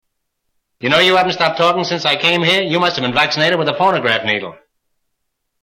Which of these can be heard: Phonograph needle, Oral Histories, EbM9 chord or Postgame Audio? Phonograph needle